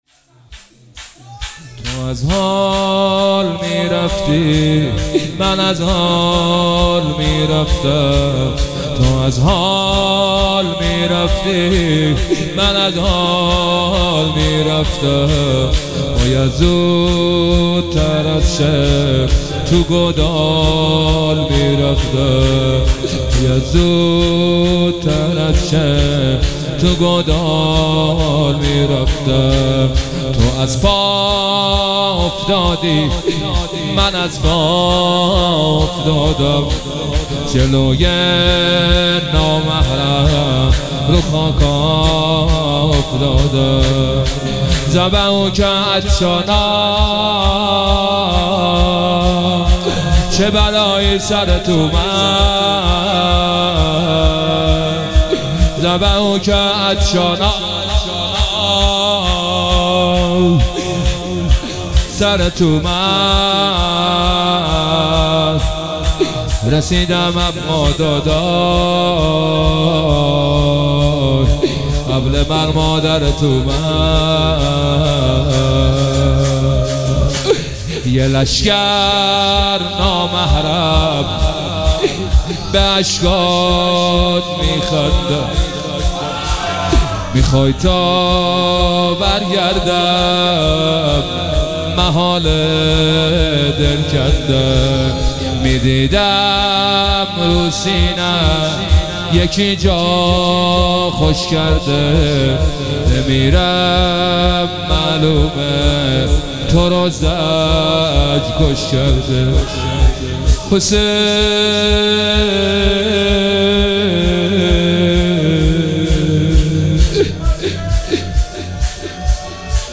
مداحی شور
هیئت روضه العباس تهران